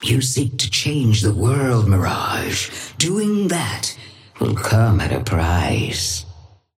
Sapphire Flame voice line - You seek to change the world, Mirage. Doing that will come at a price.
Patron_female_ally_mirage_start_01.mp3